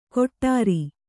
♪ koṭṭāri